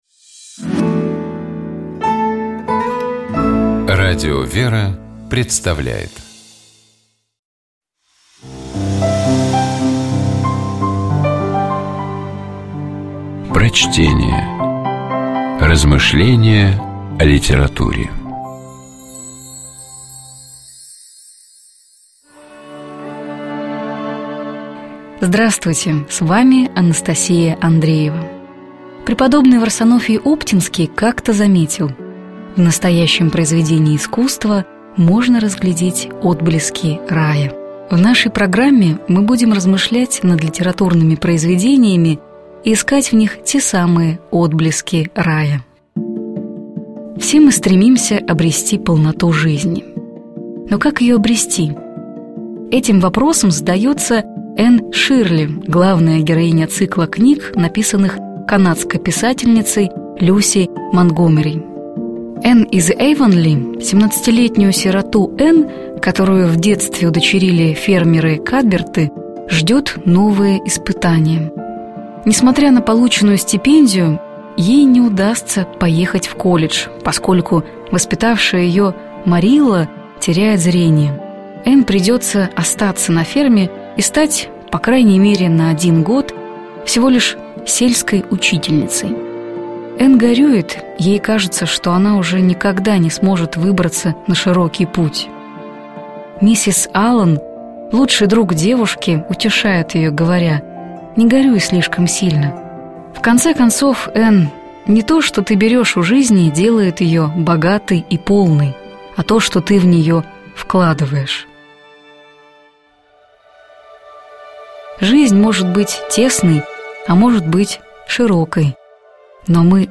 Prochtenie-Ljusi-Montgomeri-Jenn-iz-Jejvonli-Polnota-zhizni.mp3